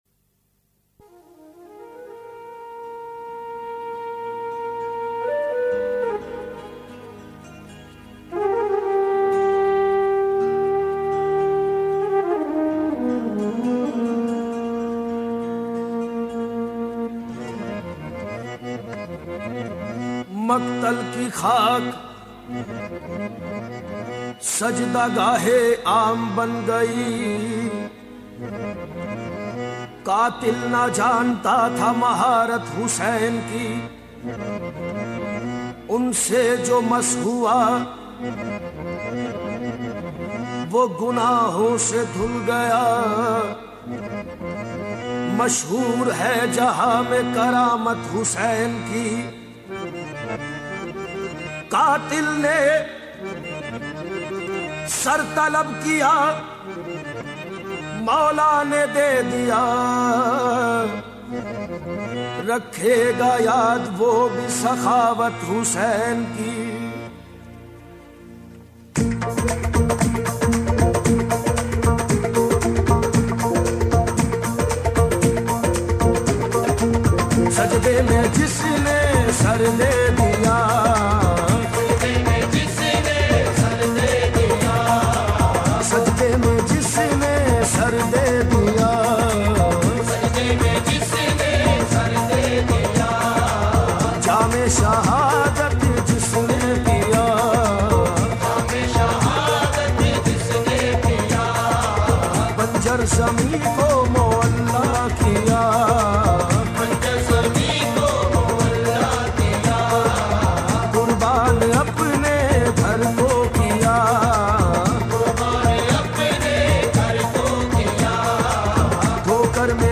Urdu Qawwali
noha